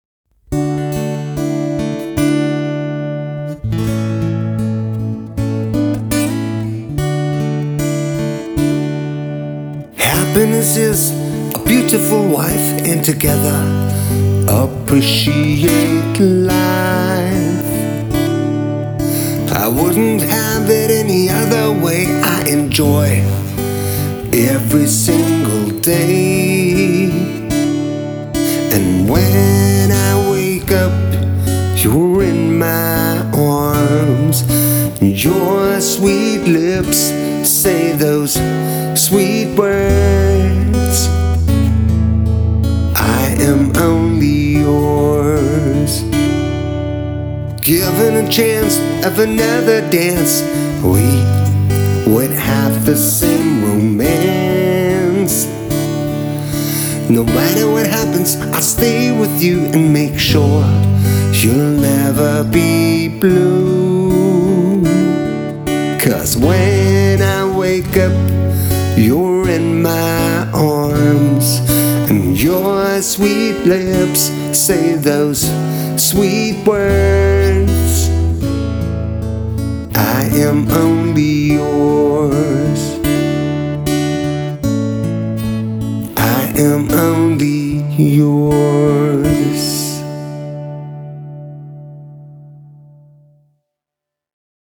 gitar